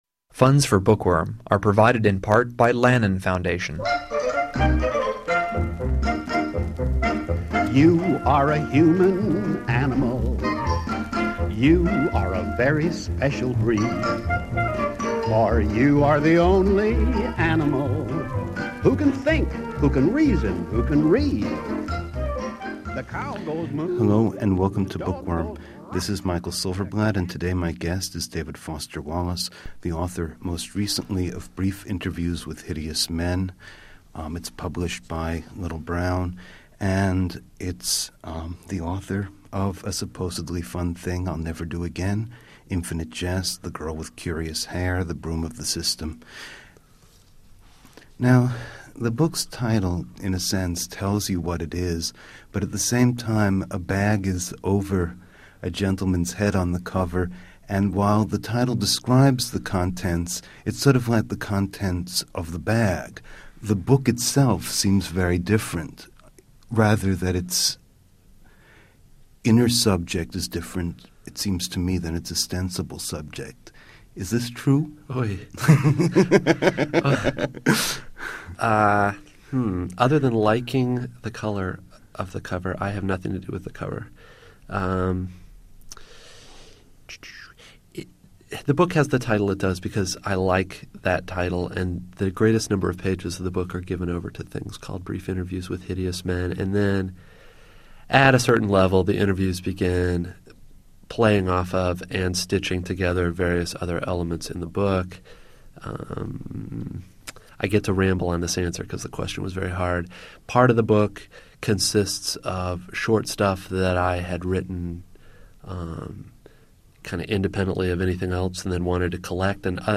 Witness the uproarious frenzy of definition when David Foster Wallace cuts loose and tries to make a straightforward statement about the hideous men (and women) in his new book.